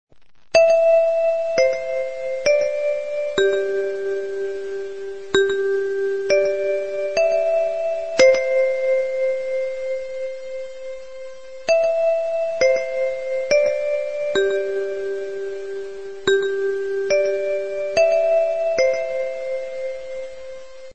预备铃.mp3
为了改善校园上、下课铃声环境，经学院研究，将传统的上课电铃声改换成音乐铃声。分为预备、上课和下课三种音乐铃声。